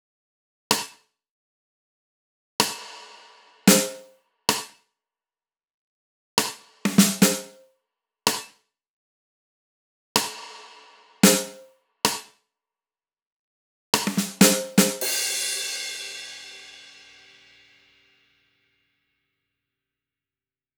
VDE 127BPM Rebound Drums Fills.wav